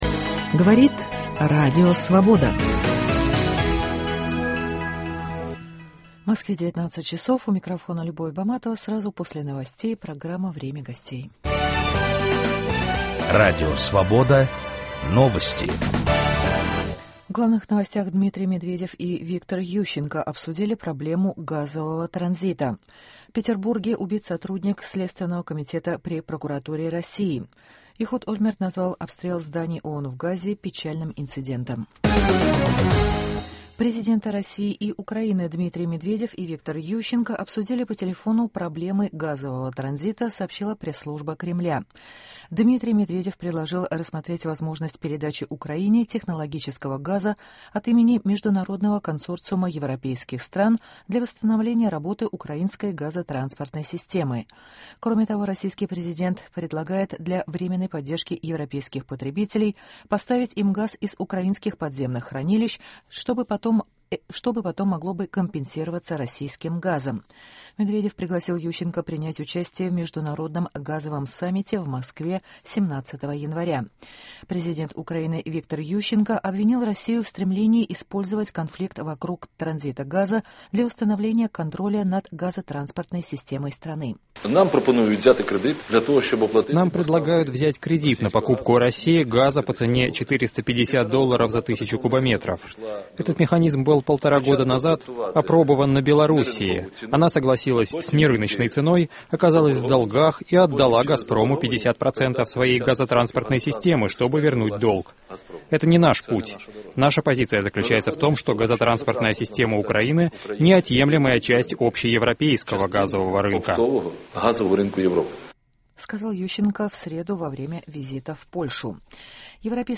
Гость - заместитель руководителя фракции «Справедливая Россия» в Государственной Думе России, сопредседатель объединения рабочих профсоюзов «Защита труда» Олег Шеин